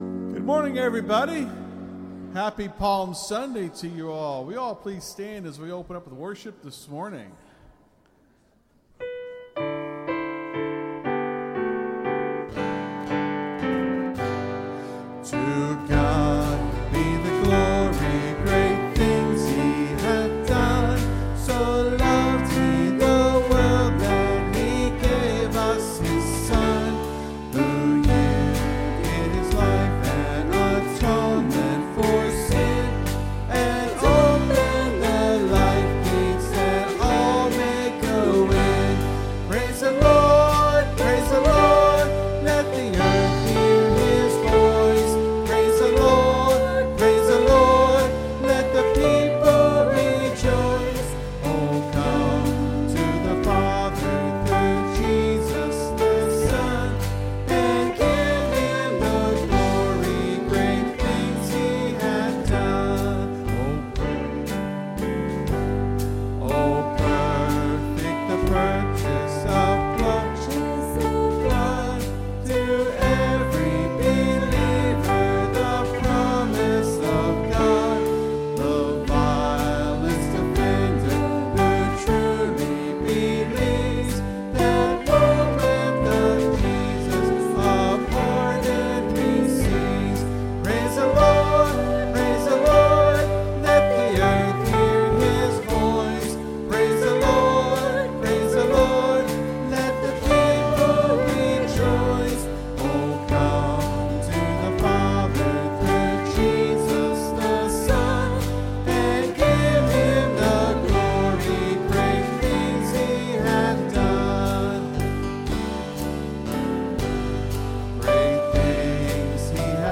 (Sermon starts at 26:40 in the recording).